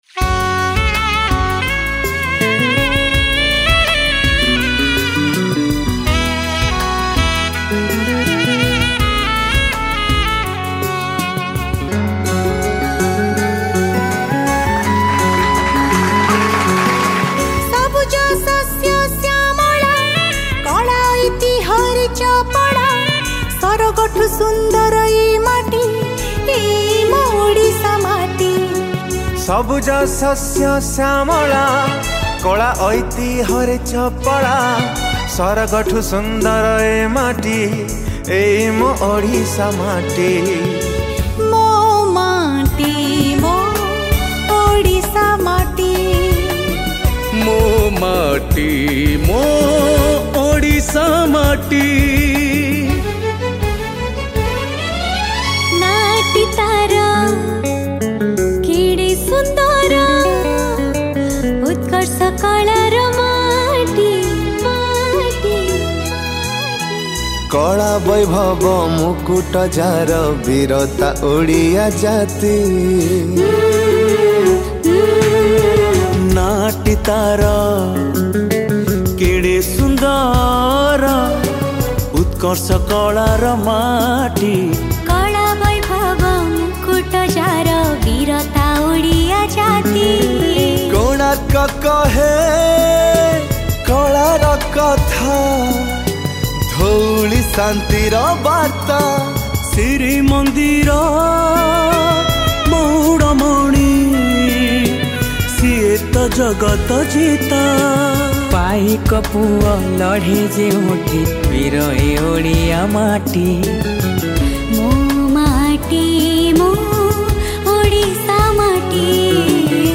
Independents Day & Republic Day Special Song Songs Download